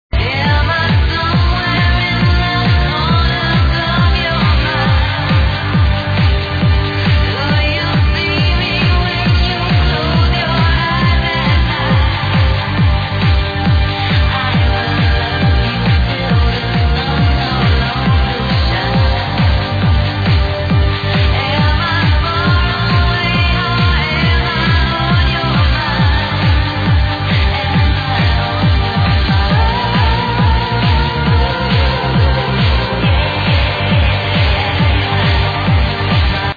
The song sounded somewhat progressive.